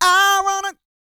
E-GOSPEL 247.wav